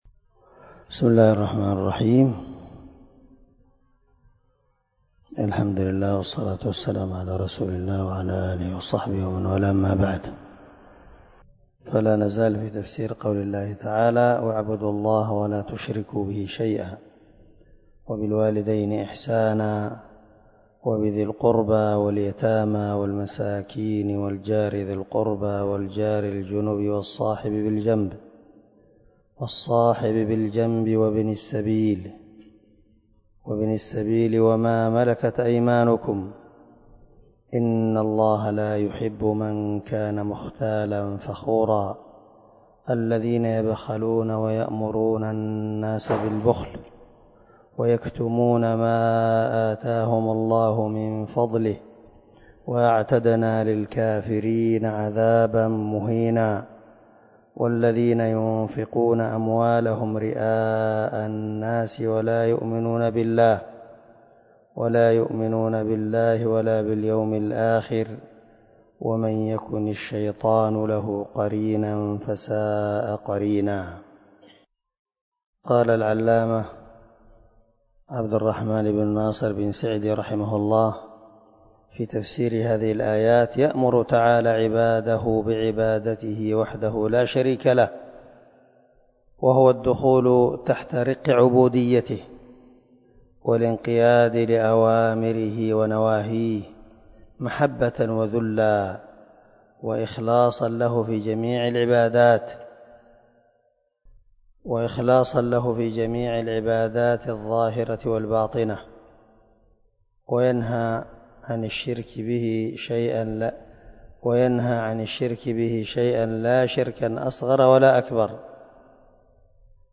262الدرس 30 تابع تفسير آية ( 36 – 38 ) من سورة النساء من تفسير القران الكريم مع قراءة لتفسير السعدي